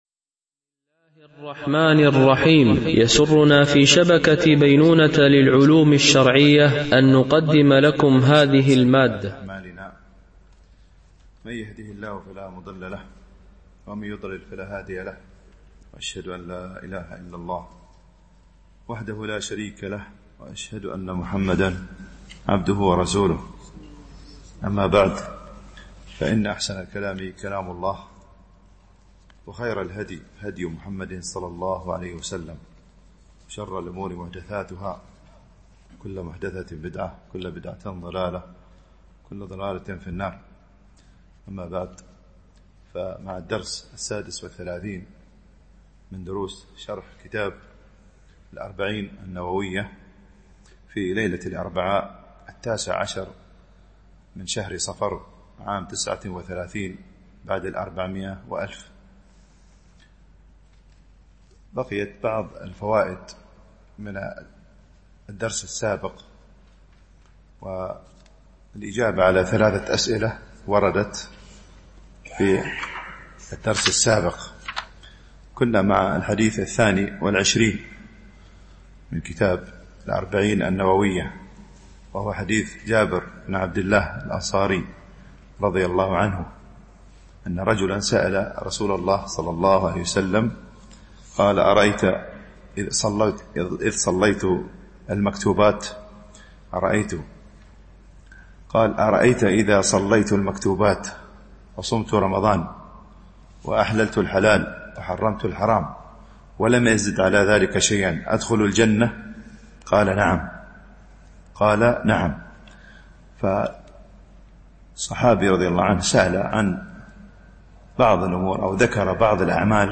شرح الأربعين النووية ـ الدرس 36 (الحديث 22)